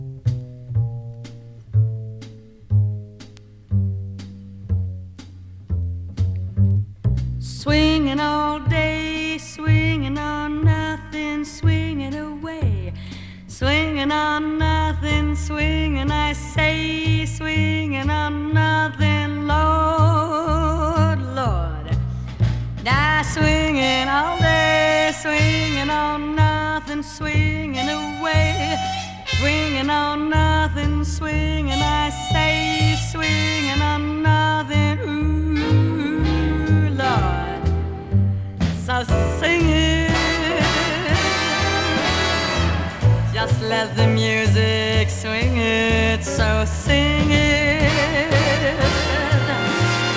洋楽JAZZ/FUSION